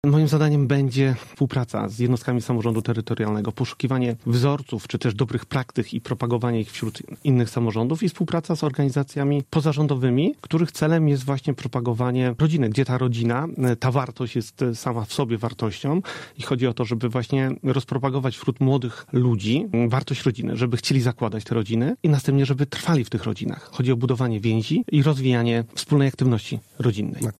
Grzesiowski, który był porannym gościem Radia Zachód, mówi, że pełnomocnik jest łącznikiem miedzy samorządowcami a wojewodą w kwestiach promocji polityki prorodzinnej: